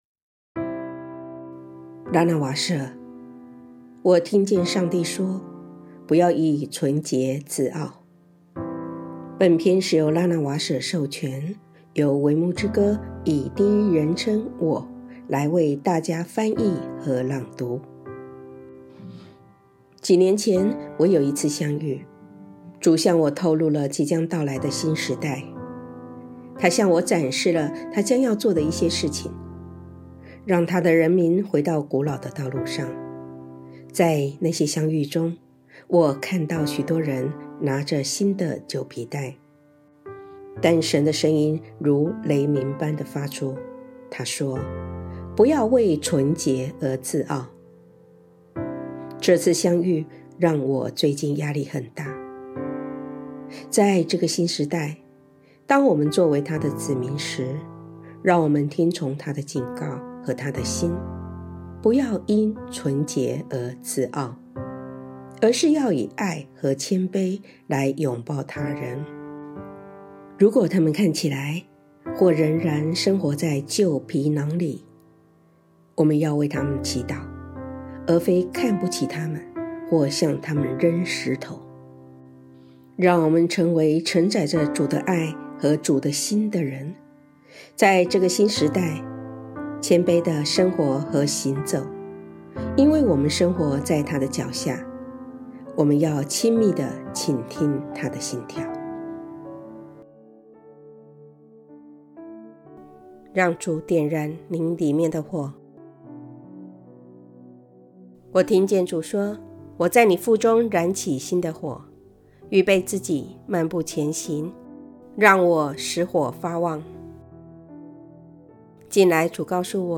本篇是由拉娜瓦舍授权，微牧之歌翻译并以第一人称“我”来为大家朗读。